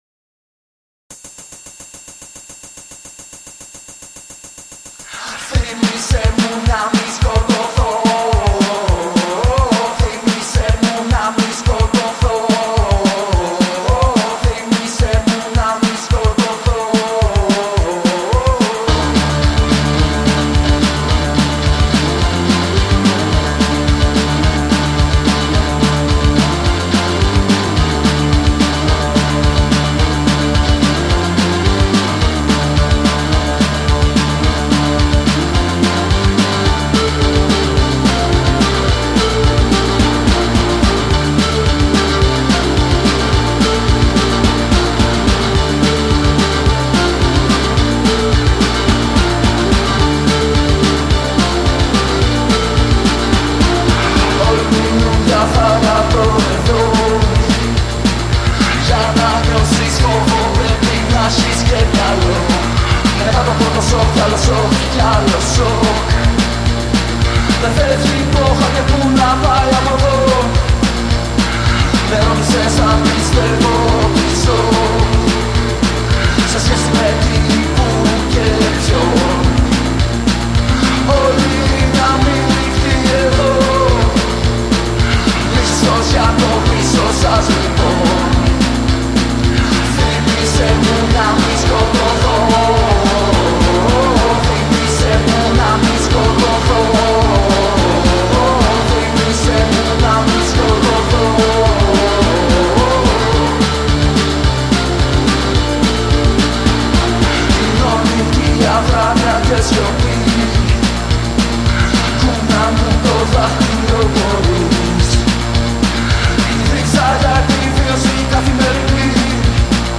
Ελληνόφωνη, σκοτεινή/φωτεινή, ποπ, γουεηβ, νταρκ, πανκ, ρέηβ